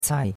cai4.mp3